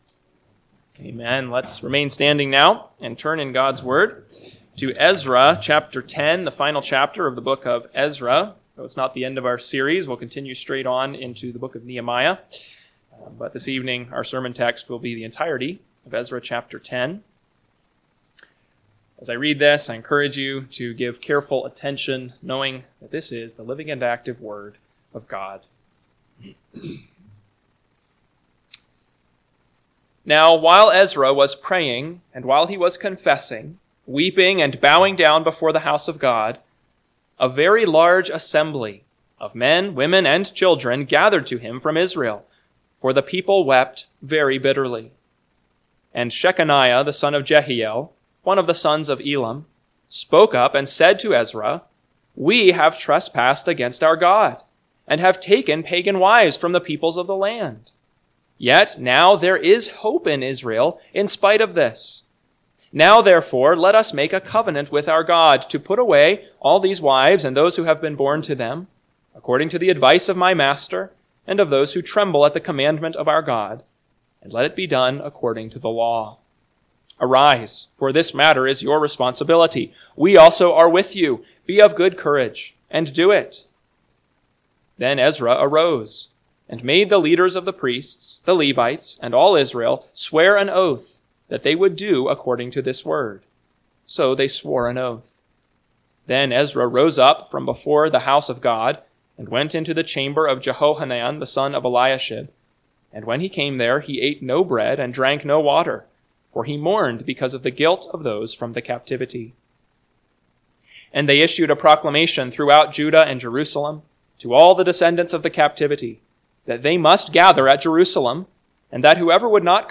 PM Sermon – 6/1/2025 – Ezra 10 – Northwoods Sermons